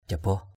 /ʥa-bɔh/ (cv.) cabaoh c_b<H [A, 124]